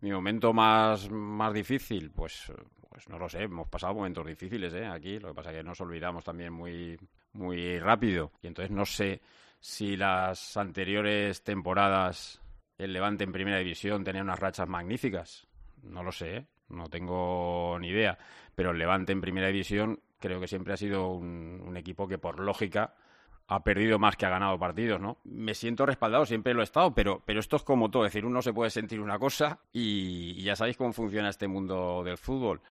Desde que yo estoy aquí hemos estado así siempre: que si UEFA, que si bajamos, que si bajamos y que si UEFA ”, dijo Paco López en la rueda de prensa previa al partido de mañana en Zorrilla.